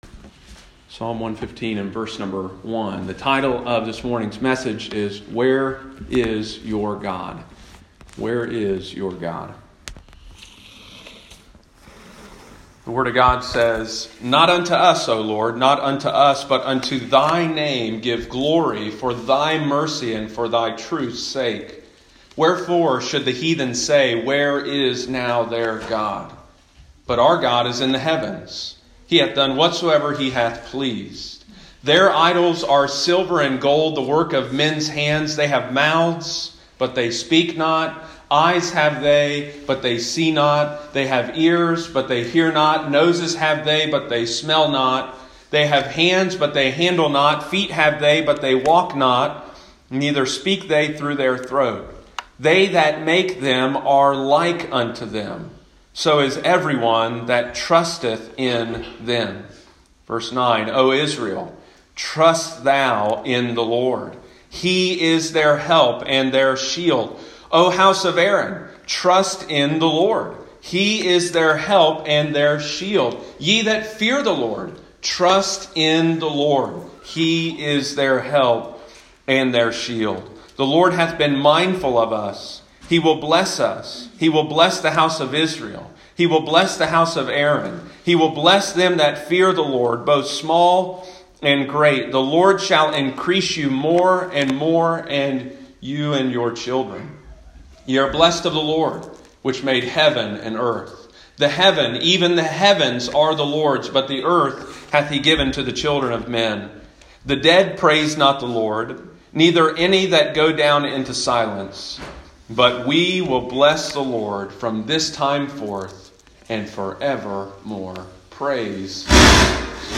Sunday morning, September 20, 2020.